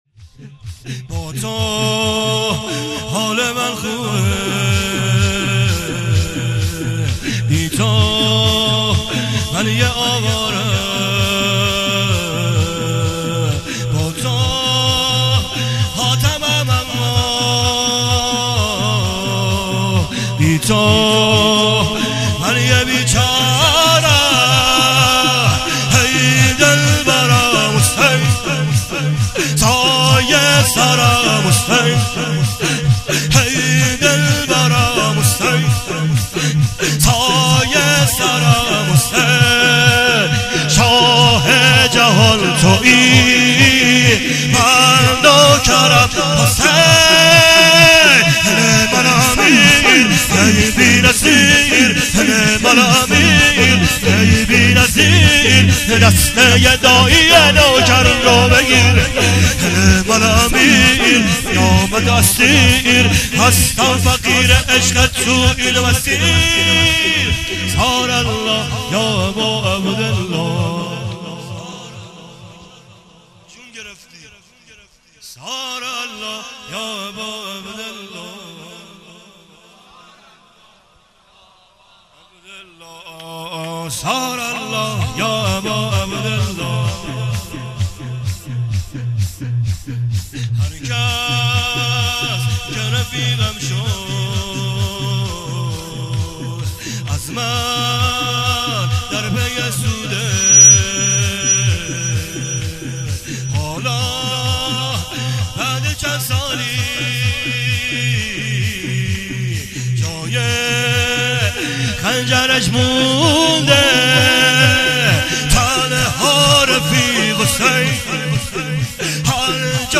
شور فوق العاده
هیئت علمدار کرمان